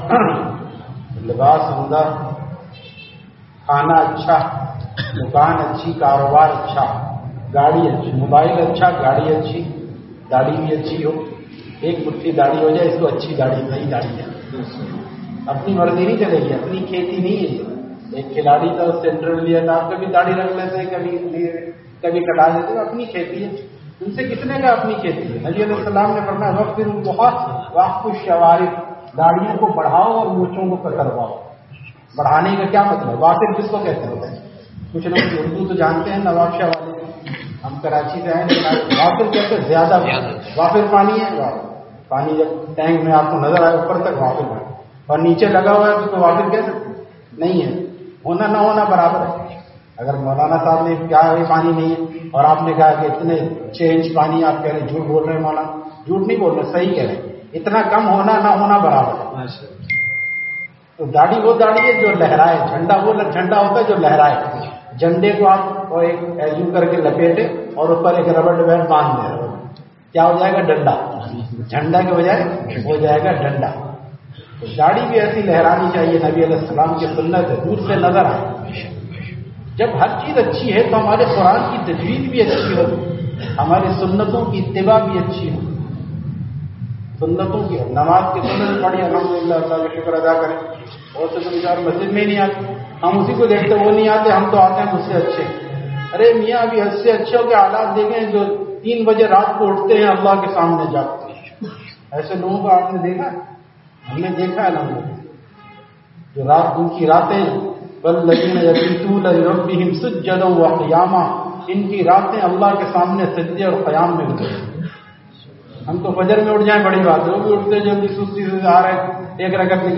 Bayan After Isha, Madina Masjid Nawabshah